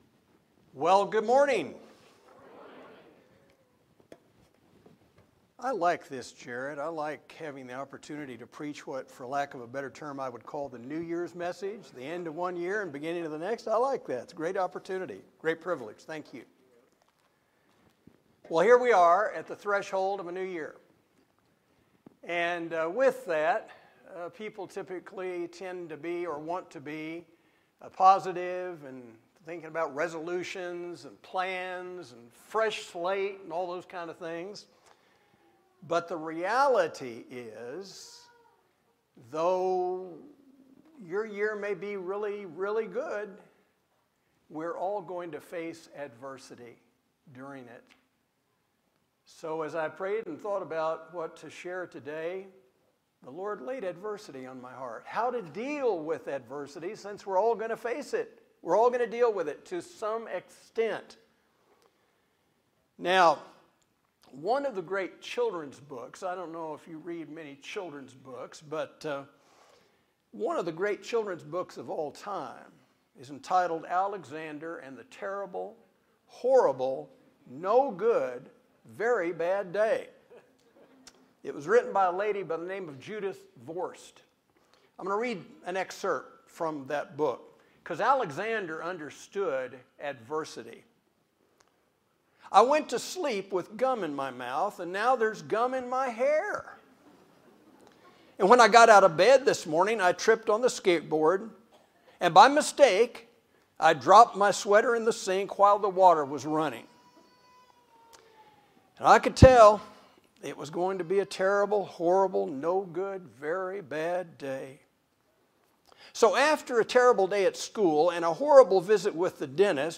These sermons are not part of a series but are separate independent single messages.